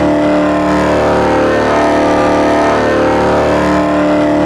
f1_v8_low.wav